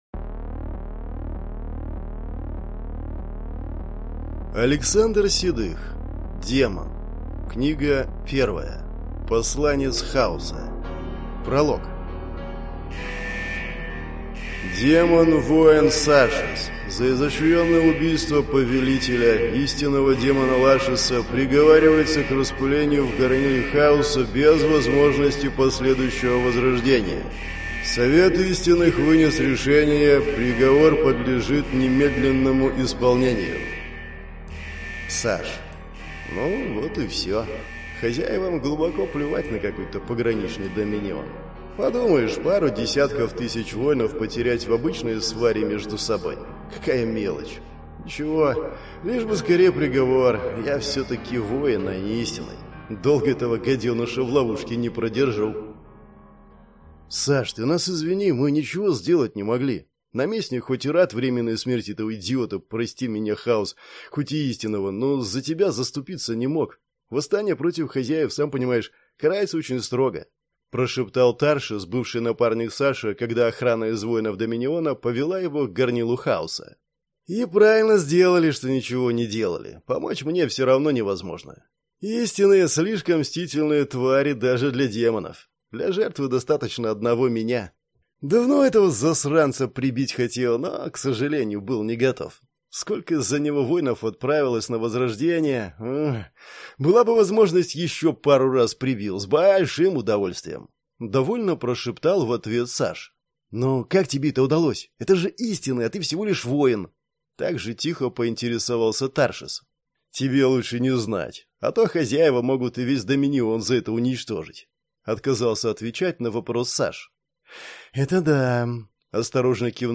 Аудиокнига Посланец хаоса | Библиотека аудиокниг